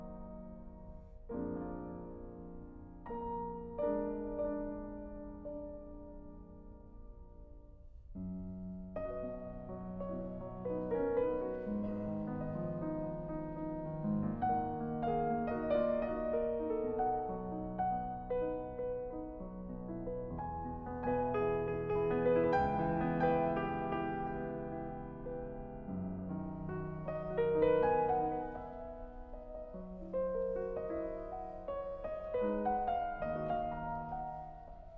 Classical Romantic Era Piano
Жанр: Классика